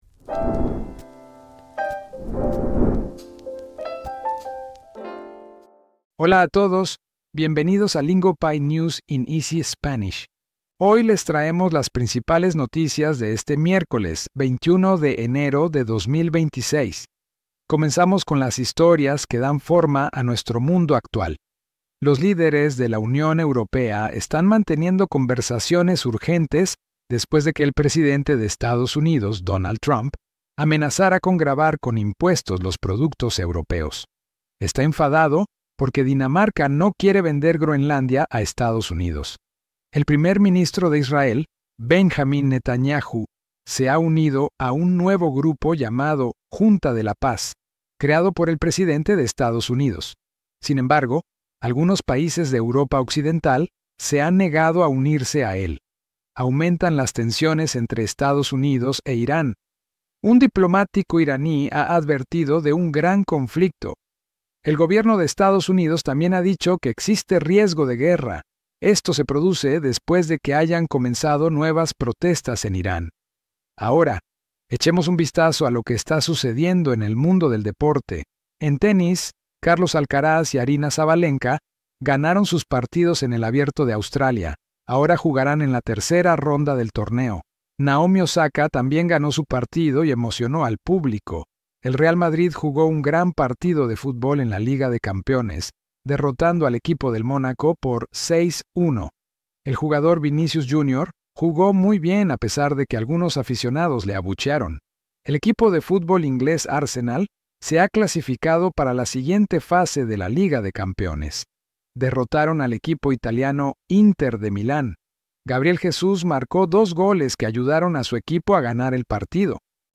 From politics to world affairs, Lingopie’s News in Easy Spanish delivers today’s headlines in clear Spanish so you grow your vocabulary in context.